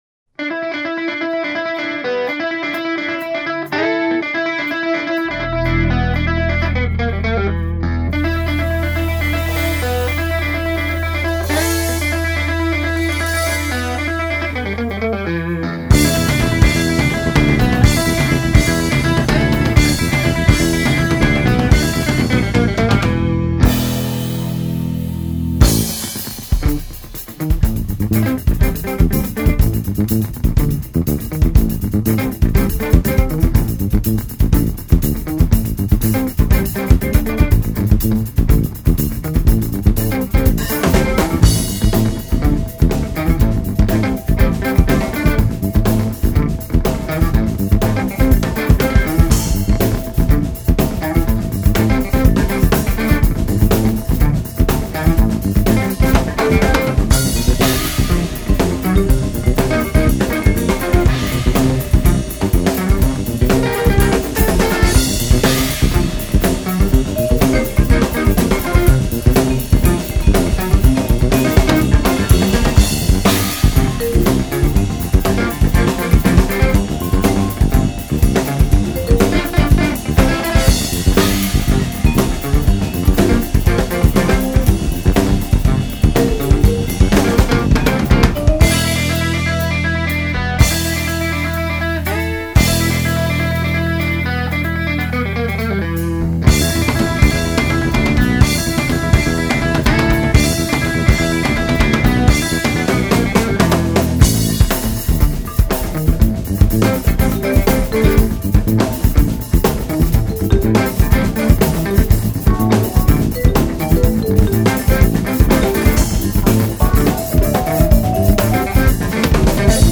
Итальянская группа прогрессивного рока